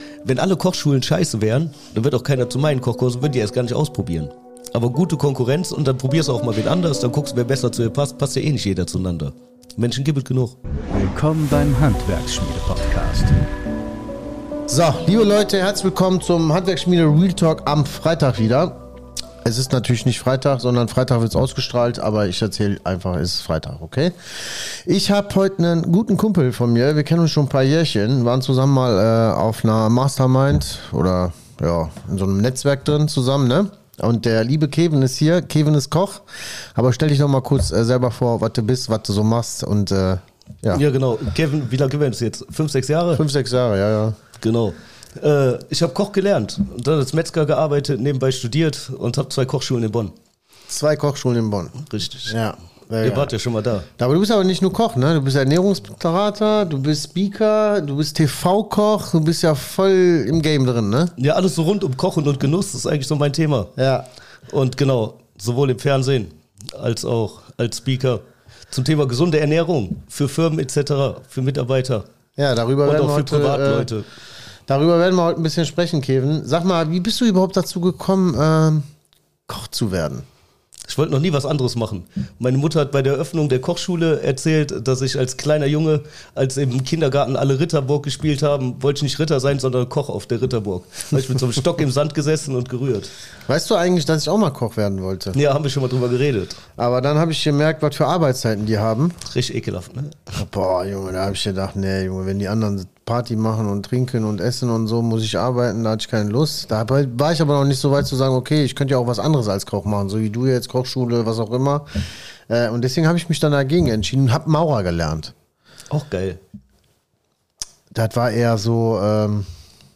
Leistung deiner Mitarbeiter steigern – dank Ernährungs-Trick | Interview